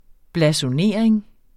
Udtale [ blasoˈneˀɐ̯eŋ ]